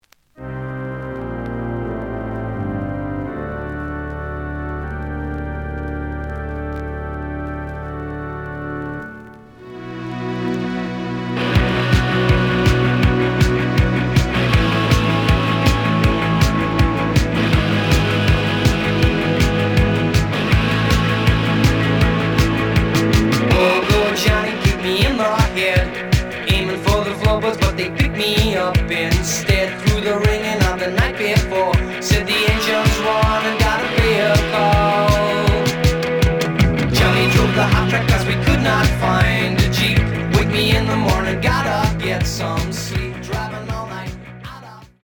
The audio sample is recorded from the actual item.
●Genre: Rock / Pop
Slight edge warp.